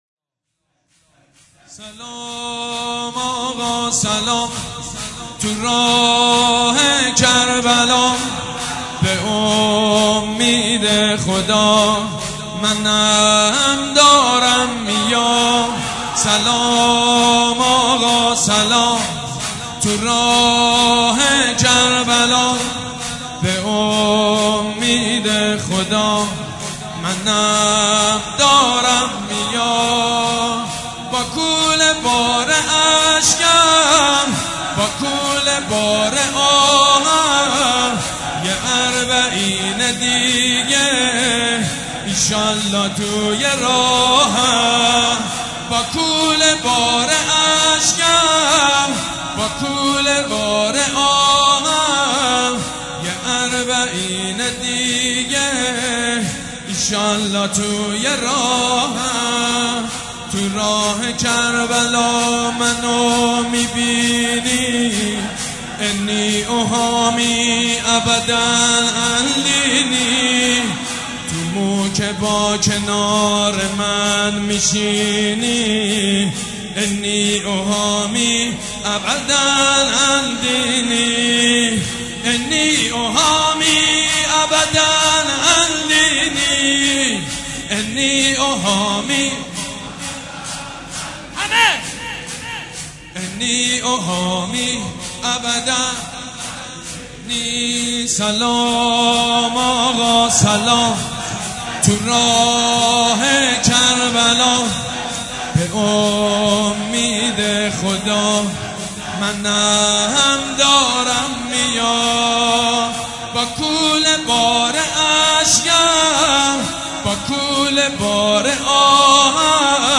مداحیاربعین